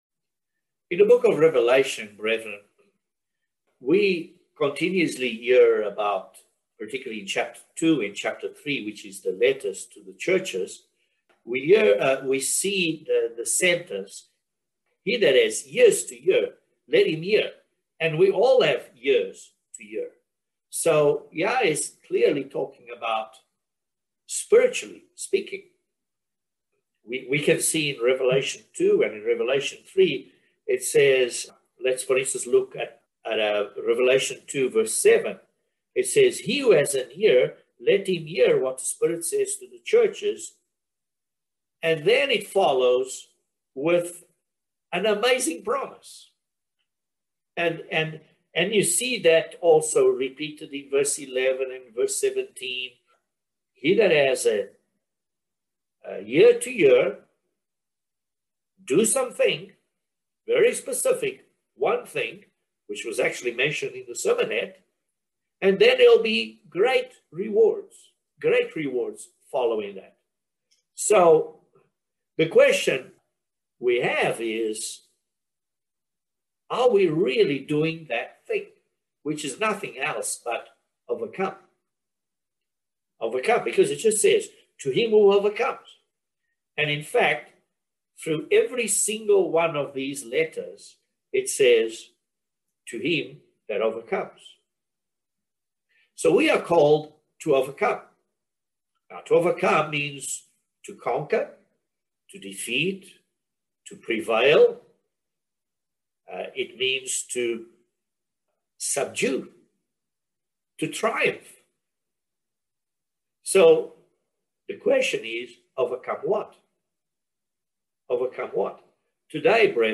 Join us for this interesting audio sermon about overcoming our human nature.